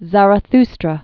(zărə-thstrə)